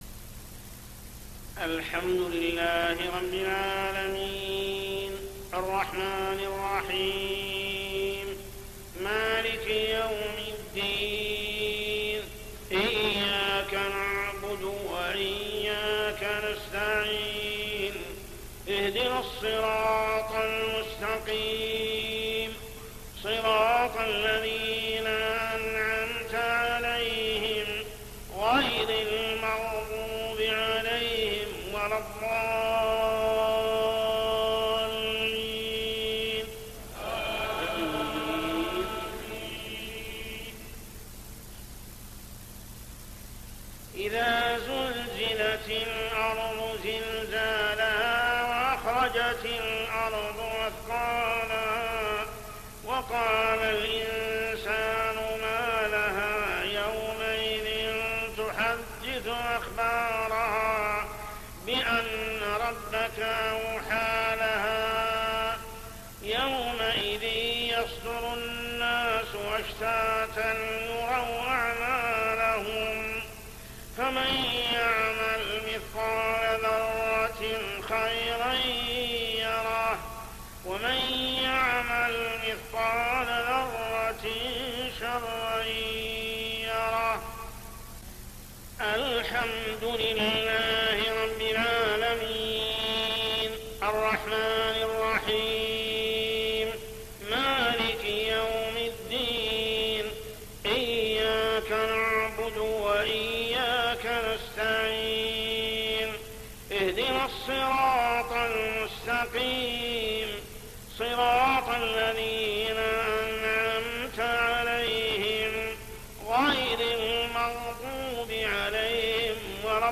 صلاة العشاء 4-9-1423هـ سورتي الزلزلة و العاديات كاملة | Isha prayer Surah Az-Zalzalah and Al-Adiyat > 1423 🕋 > الفروض - تلاوات الحرمين